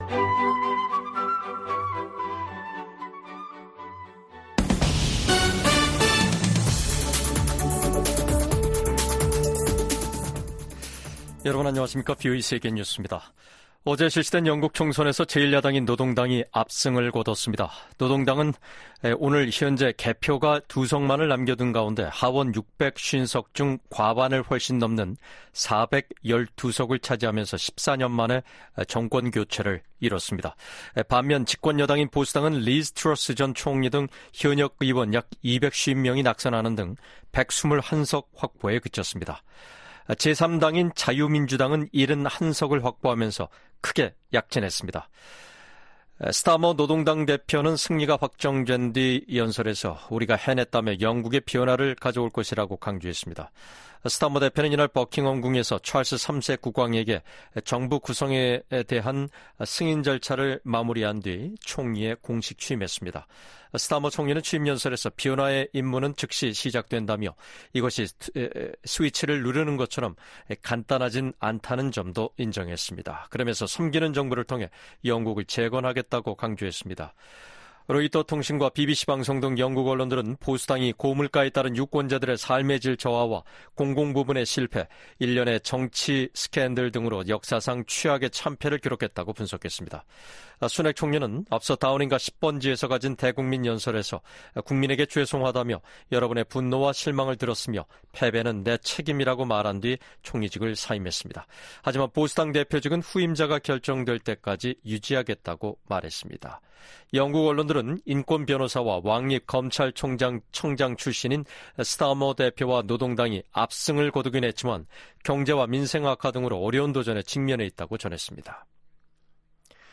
VOA 한국어 간판 뉴스 프로그램 '뉴스 투데이', 2024년 7월 5일 3부 방송입니다. 미 국무부는 북한 탄도미사일이 러시아의 우크라이나 공격에 사용됐다는 분석을 제시하며, 양국에 책임을 묻겠다고 경고했습니다. 북한을 비롯한 적성국들이 미국의 첨단 기술을 무단으로 사용하고 있는지 파악해 대응하도록 하는 법안이 미 하원에서 발의됐습니다.